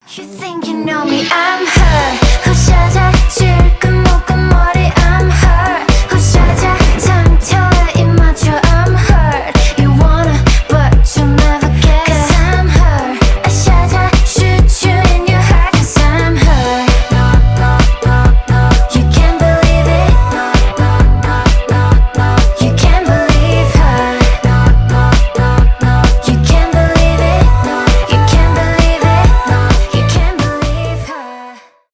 k-pop